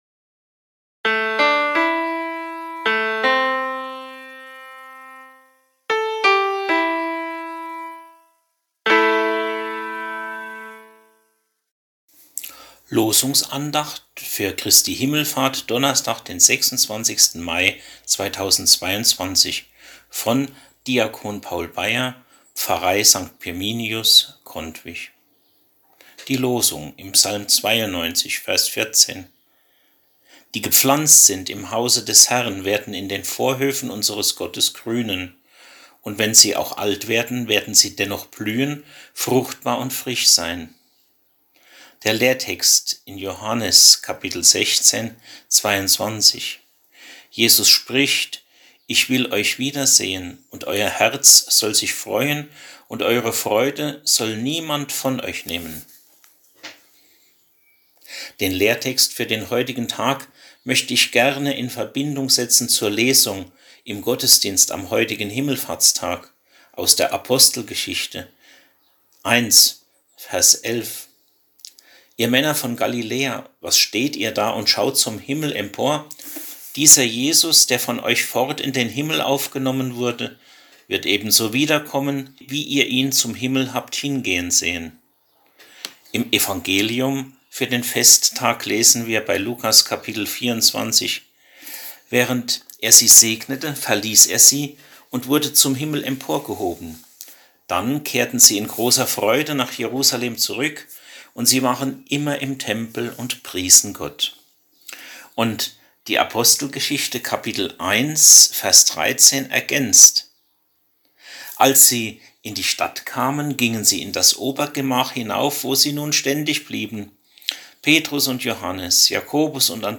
Losungsandacht für Donnerstag, 26.05.2022